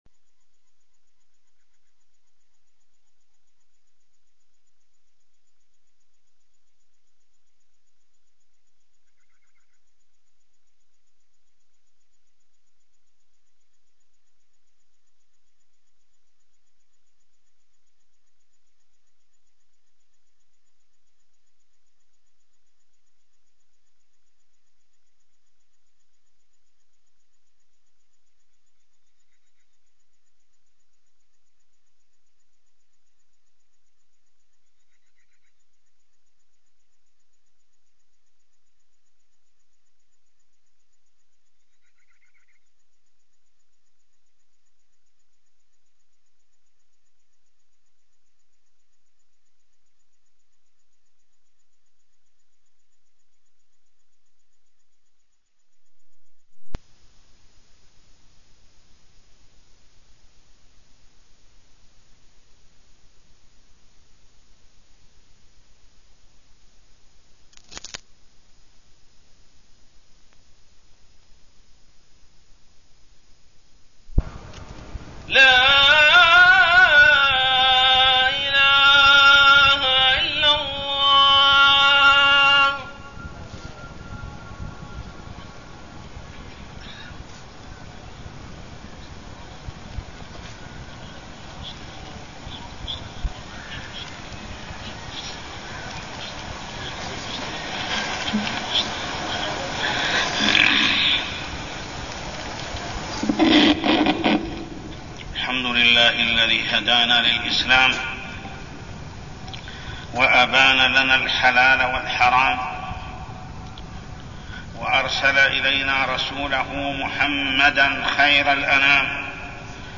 تاريخ النشر ٢٨ شعبان ١٤١٦ هـ المكان: المسجد الحرام الشيخ: محمد بن عبد الله السبيل محمد بن عبد الله السبيل جارحة اللسان